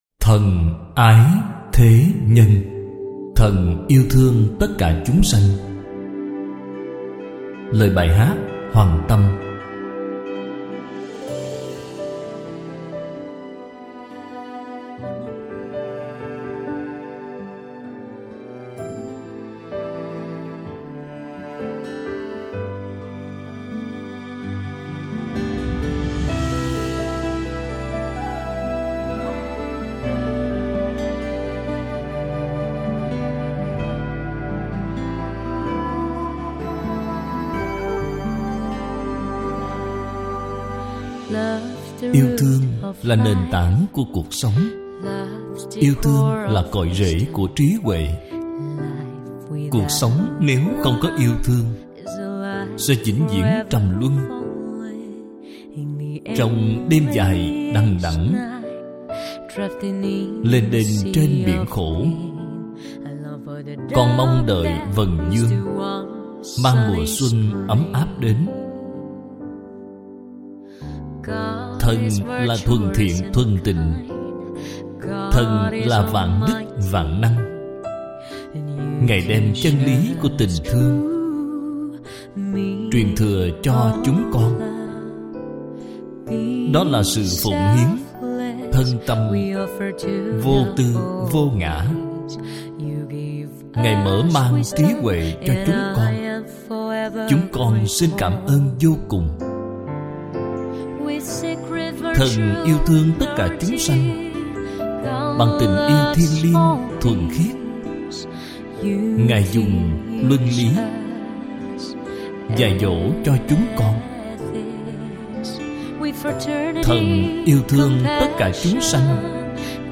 Bai Hat Than Ai The Nhan - hat tieng Anh, doc tieng Viet.mp3